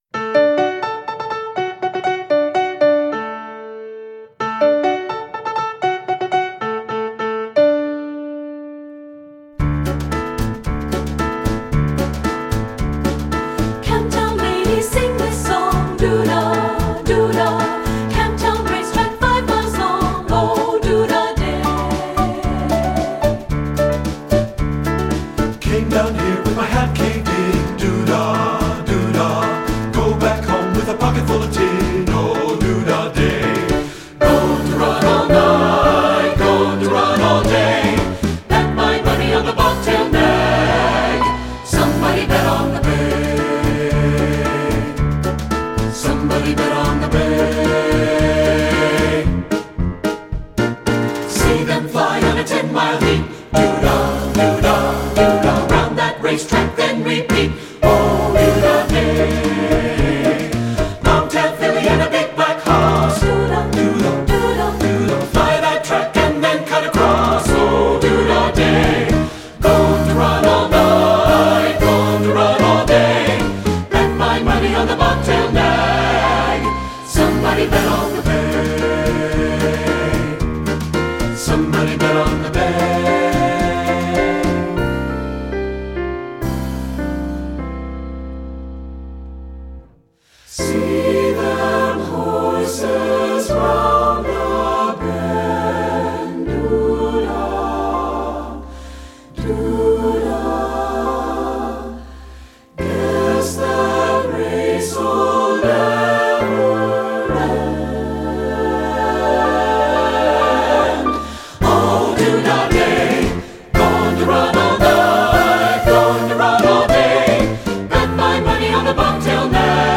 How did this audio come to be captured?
2-Part (SATB recording)